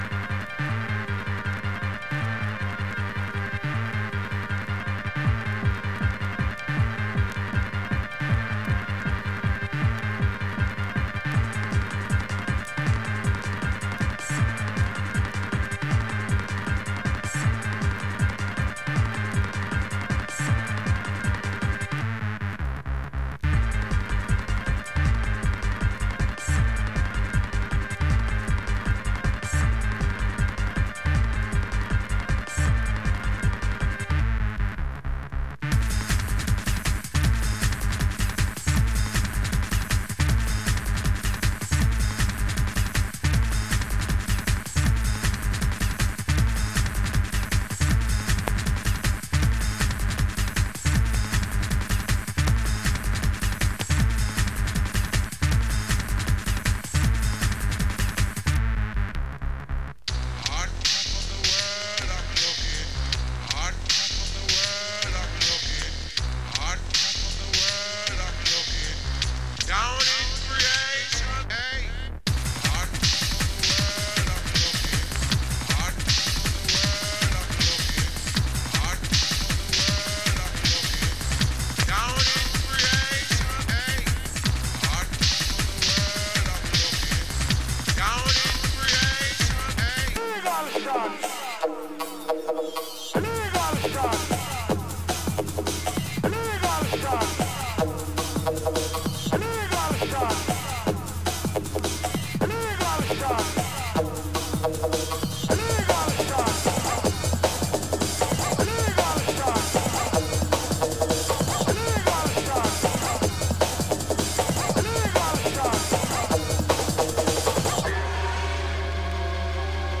Metalwork Acetate Dubplate